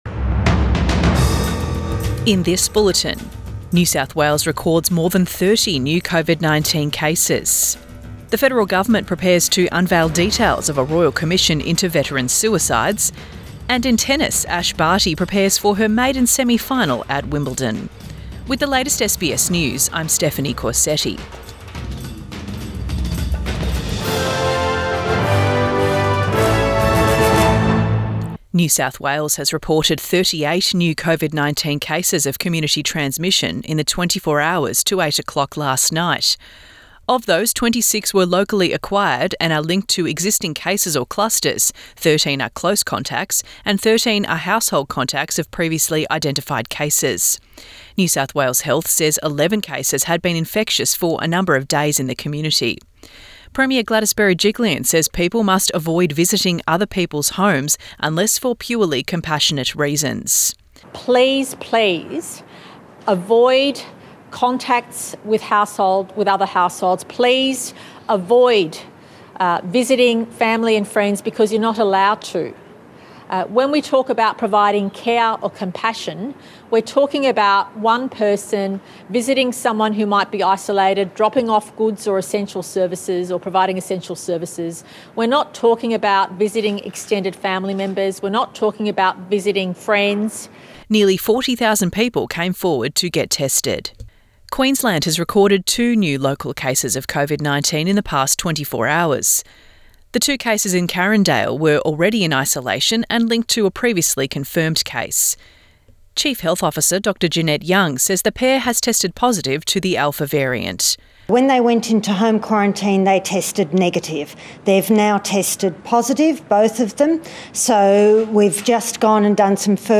Midday bulletin 8 July 2021